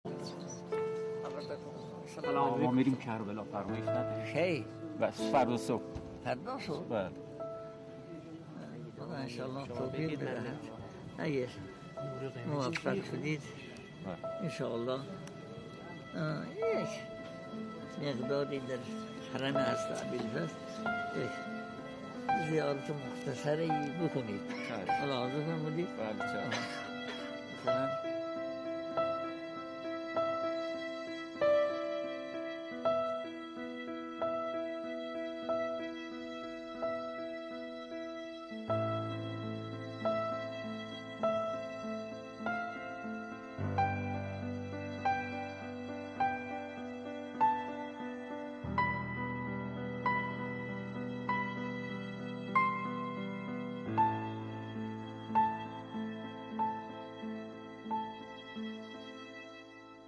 سخنان آیت الله بهجت با موضوع نایب الزیاره حرم حضرت عباس(ع)